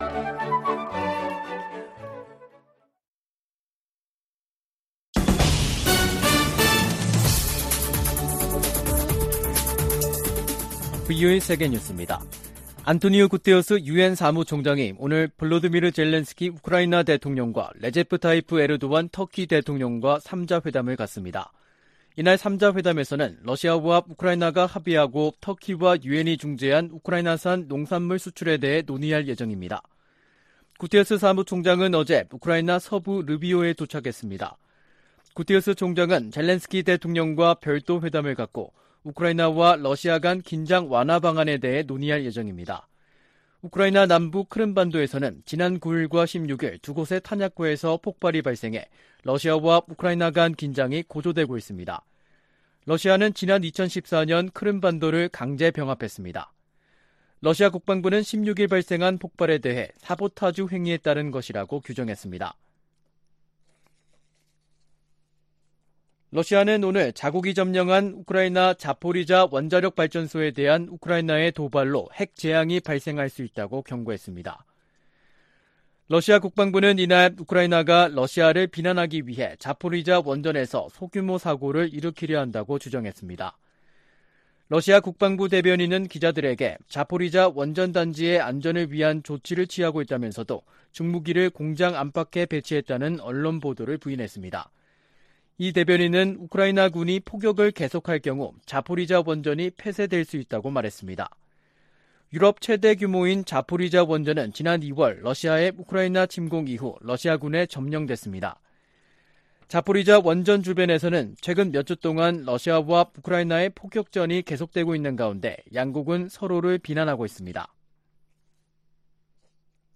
VOA 한국어 간판 뉴스 프로그램 '뉴스 투데이', 2022년 8월 18일 2부 방송입니다. 한국 정부가 ‘담대한 구상’과 관련한 구체적인 대북 메시지를 발신하고 북한이 수용할 수 있는 여건을 조성해나갈 것이라고 밝혔습니다.